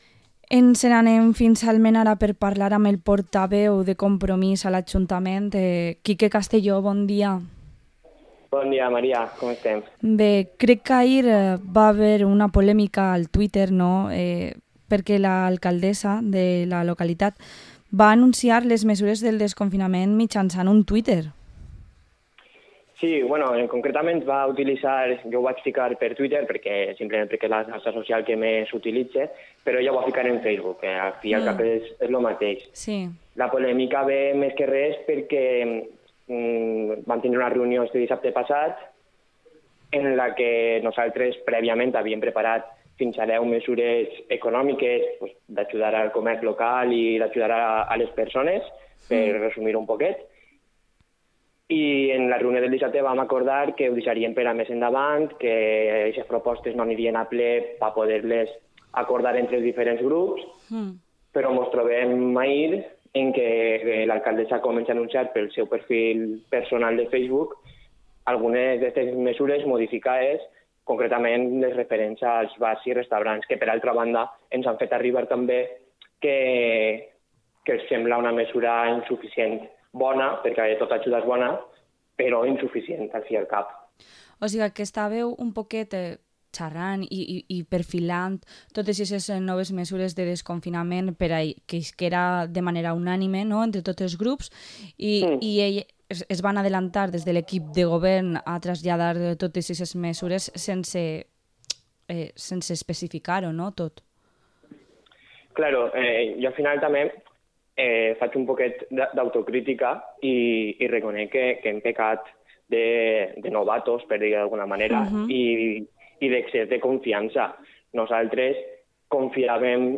Entrevista al concejal de Compromís en Almenara, Quique Castelló